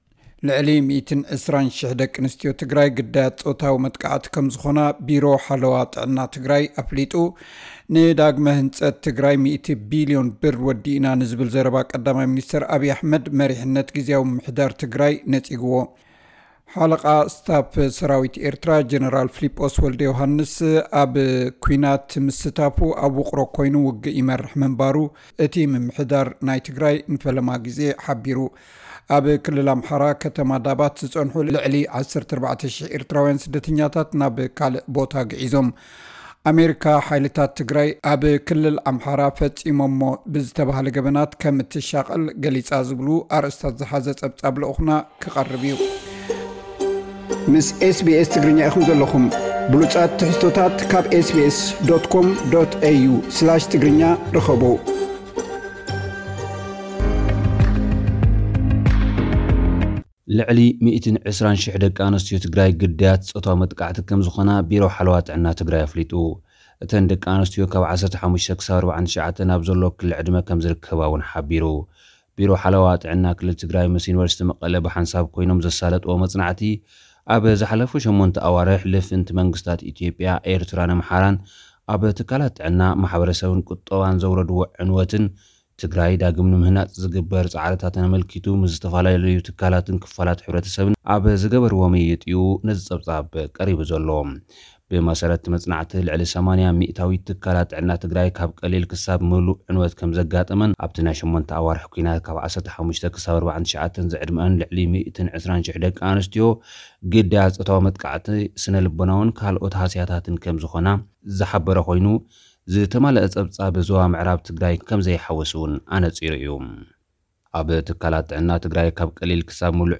ዝብሉ ኣርእስታት ዝሓዘ ጸብጻብ ልኡኽና ቀሪቡ ኣሎ።